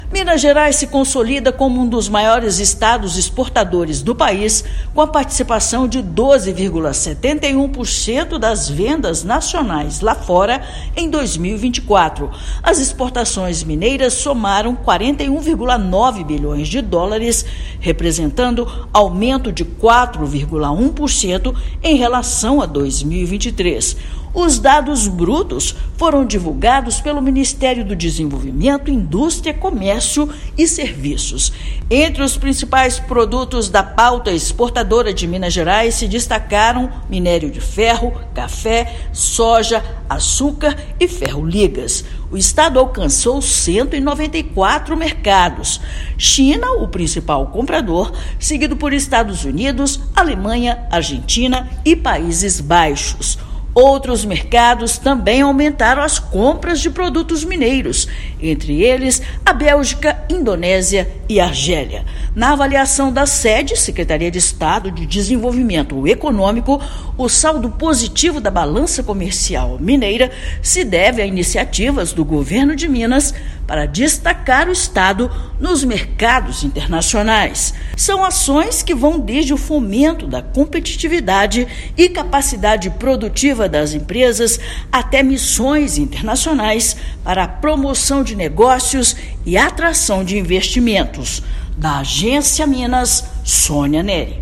[RÁDIO] Minas Gerais encerra 2024 com US$ 41,9 bilhões em exportações e se mantém como o terceiro maior exportador do Brasil
Estado fechou o ano com superávit de US$ 24,9 bilhões na balança do comércio exterior. Ouça matéria de rádio.